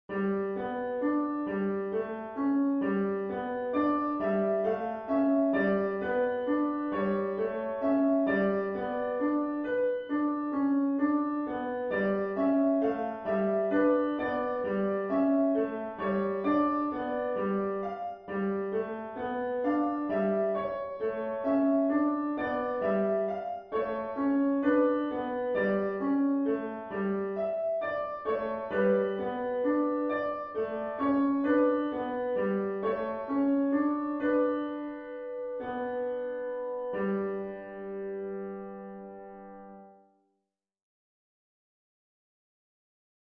ミクソリディア旋法が使われています。